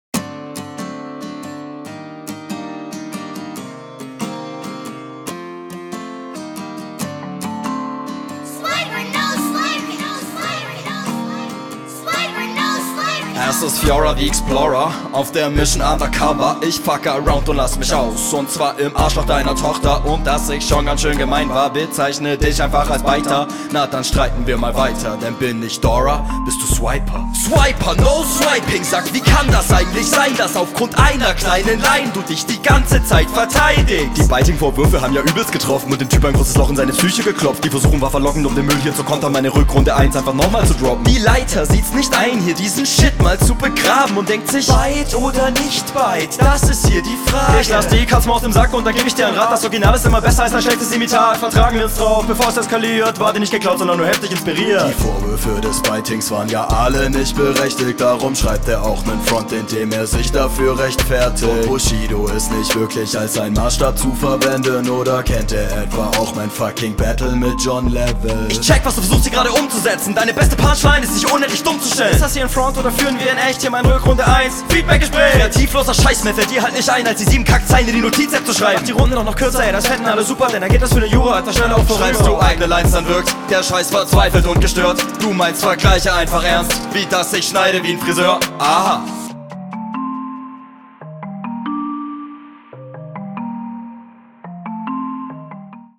ohne die doubletimes hättest du bei den flowpunkten so abgeräumt :(